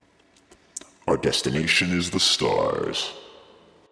描述：科幻相关的文字由男性说。用AT2020 + USB录制。有效果。